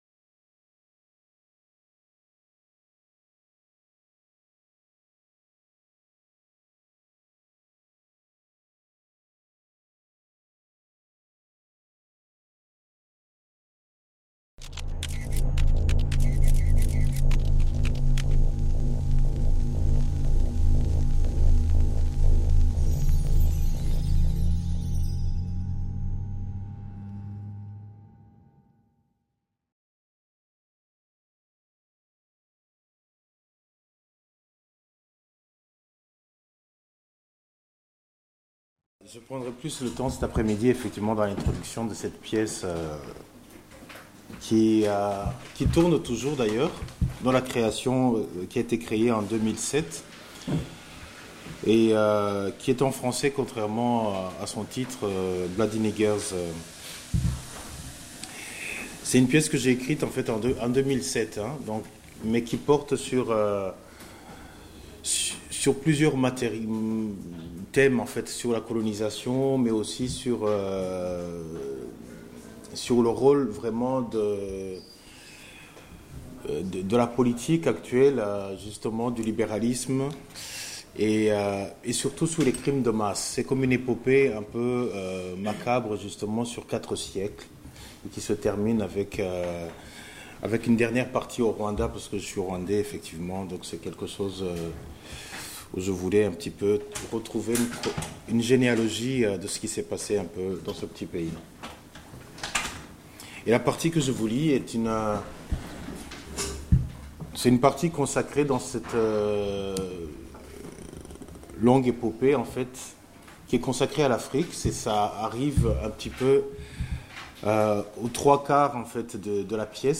commédienne